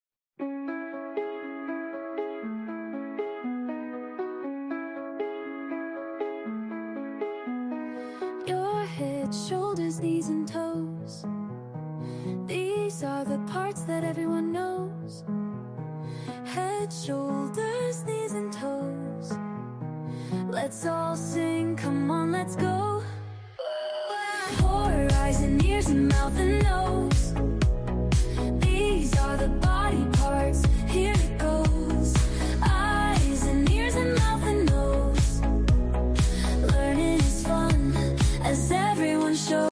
Fun Kids Song!
lively sing-along
classic children's song